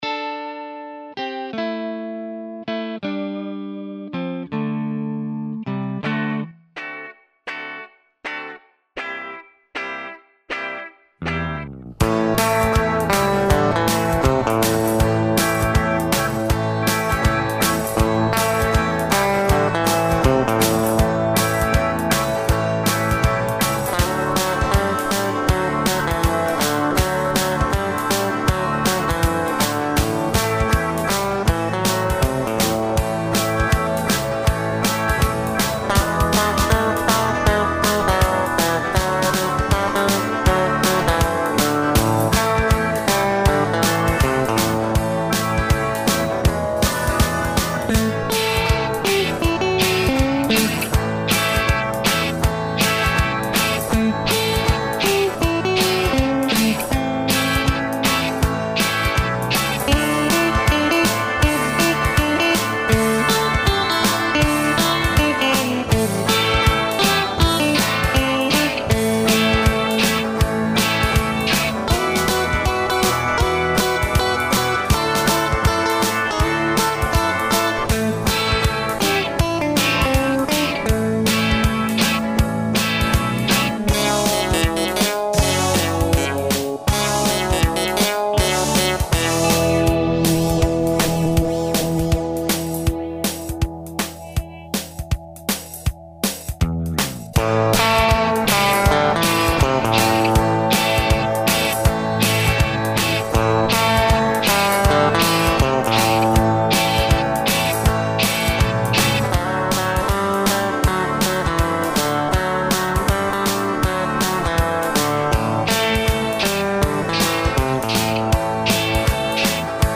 Kytaru jsem zhotovil z vraku původní kytary STAR V. Zvuk s původními snímači Brilant mi nevyhovoval a tak jsem do kytary zabudoval singly R.M.Pickups, doplněné o nástavce tvaru "U".
Pro zvýšení zvukových možností jsem doplnil elektrické zapojení o přepínač fáze kobylového snímače. Tak se mi podařilo zachovat moc hezký původní vzhled kytary a upravit její zvuk podle potřeb mého studia.